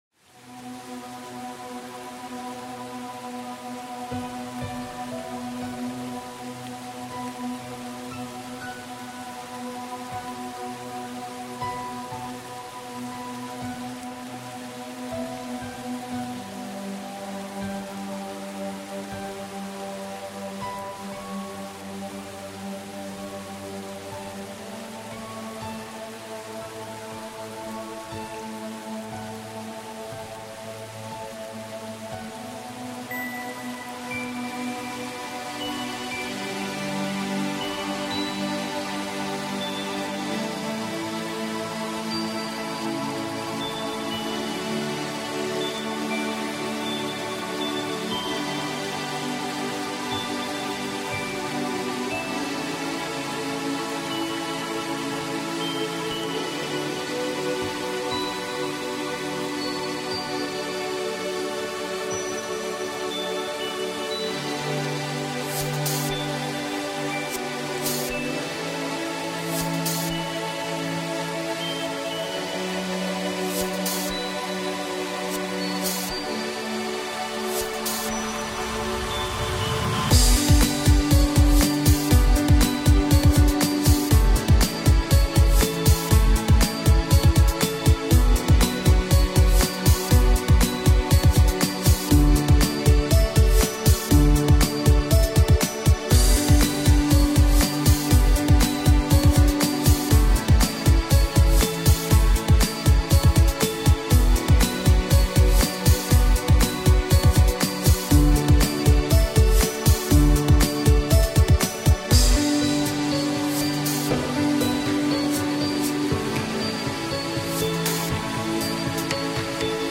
I really love her ambient and beautiful music.